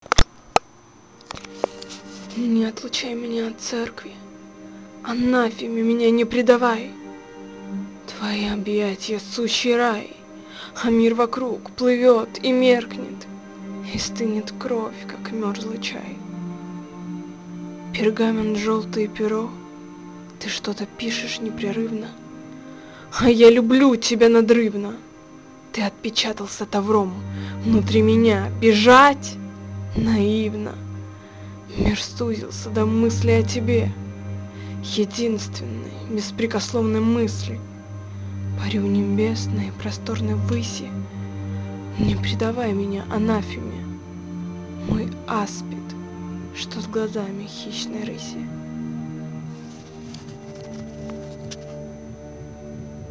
ты с таким... надрывом читаешь стихи... я просто тащусь... apple по настоящему!!